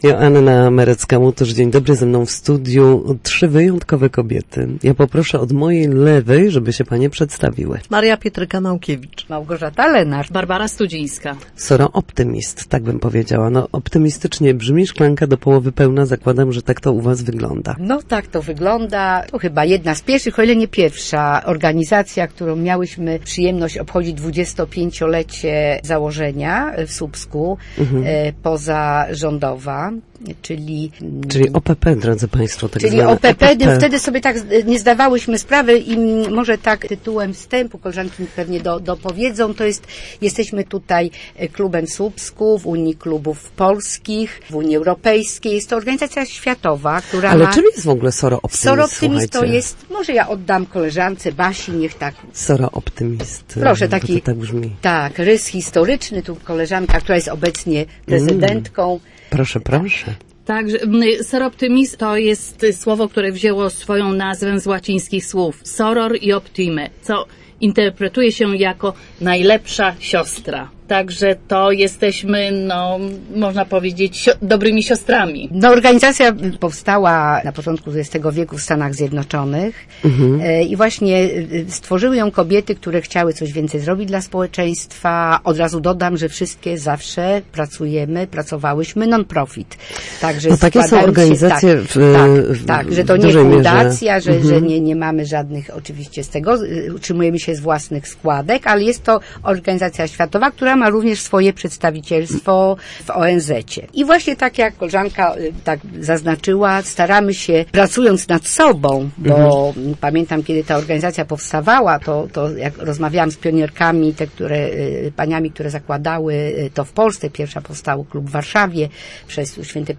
Na naszej antenie opowiedziały, czym zajmuje się organizacja i jaka jest jej historia.